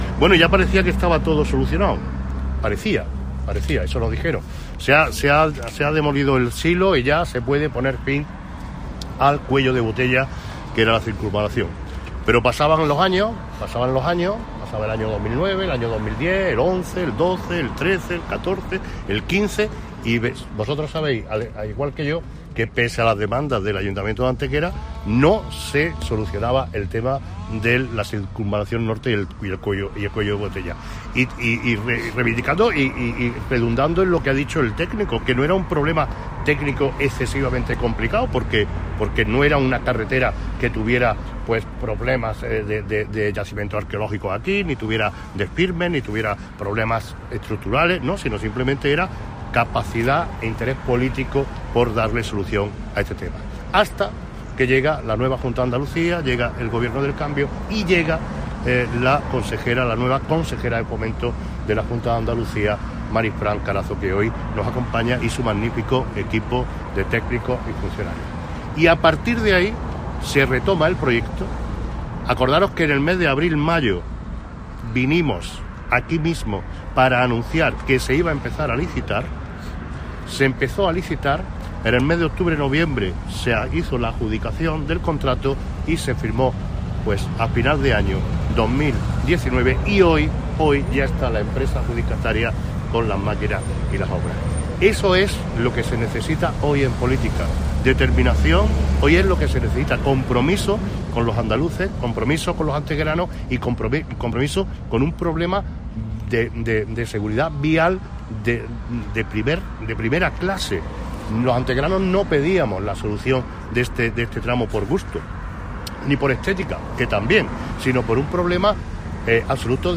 Valoración del Alcalde de Antequera.
Cortes de voz